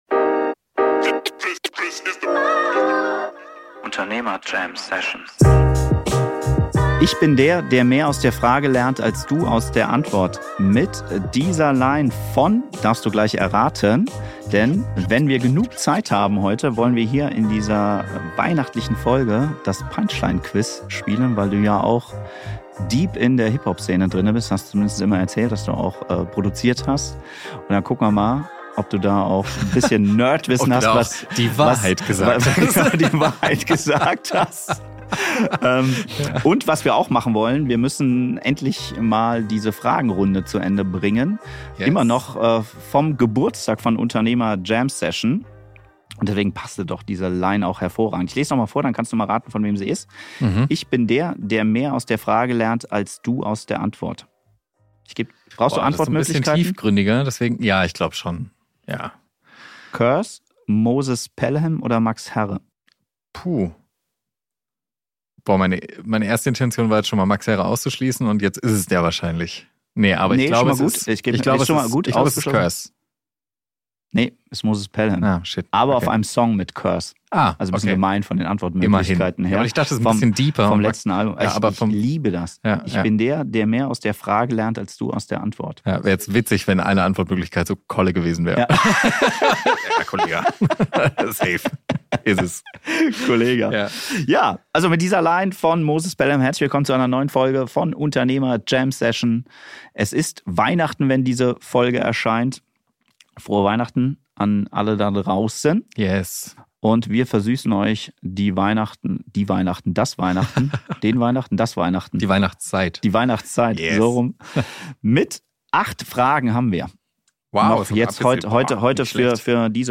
Ihr erlebt u.a. den ersten Lachanfall bei Unternehmer Jam-Session.